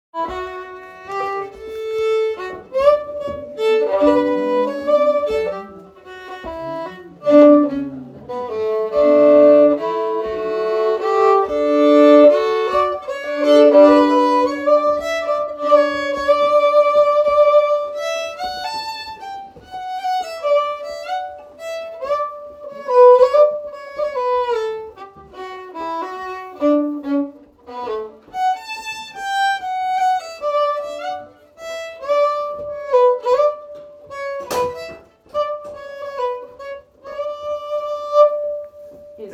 Sessions are open to all instruments and levels, but generally focus on the melody.
Composer Traditional Type Waltz Key G Recordings